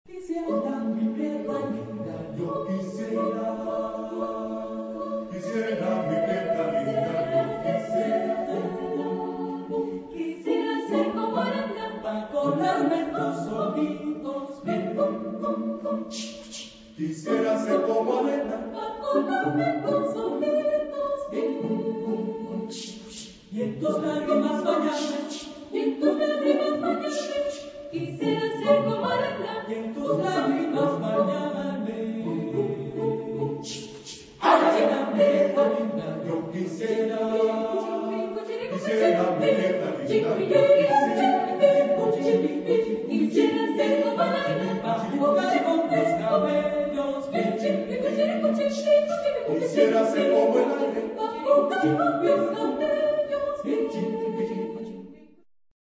Genre-Style-Forme : Folklore ; Danse
Type de choeur : SATB  (4 voix mixtes )
Tonalité : la majeur